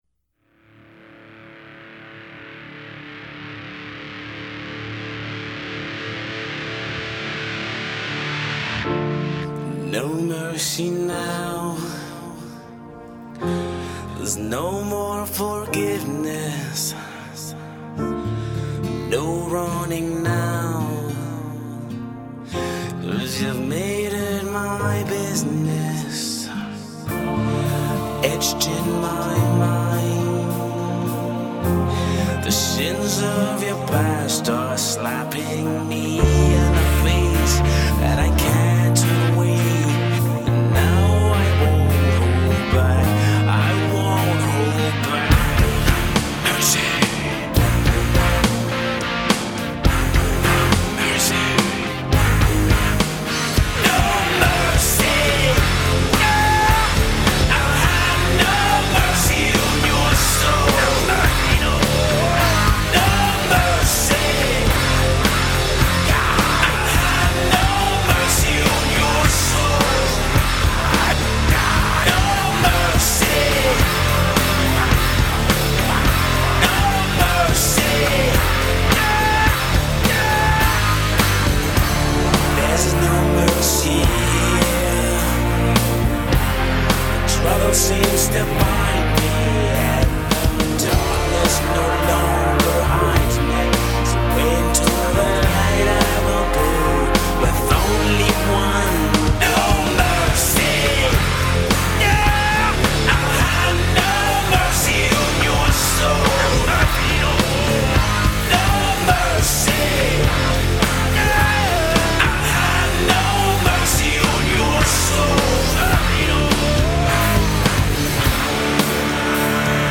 (Starting out soft, piano playing in background)